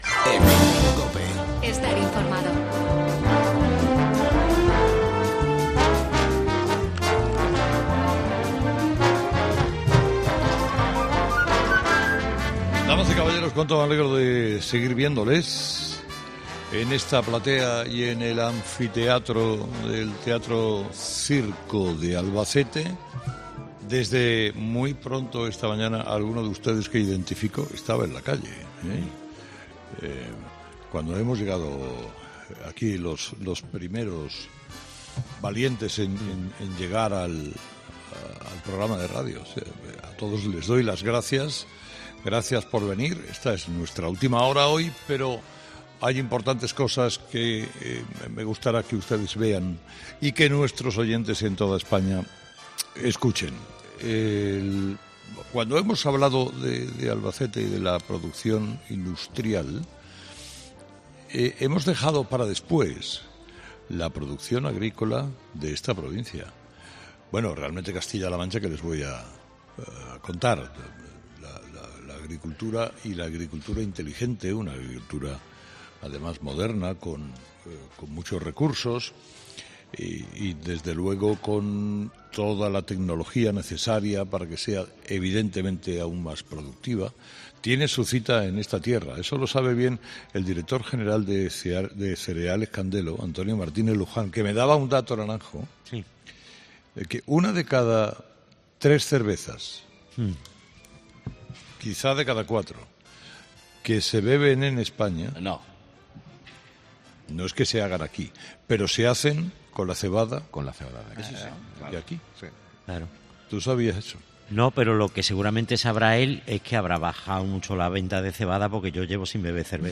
El equipo de ' Herrera en COPE ' ha estado en Albacete haciendo un programa especial para reivindicar a una de las capitales más importantes de Castilla La Mancha y el lugar en el que la empresa Cereales Candelo ha decidido situar su trabajo, especializado en producción industrial , concretamente en la agrícola .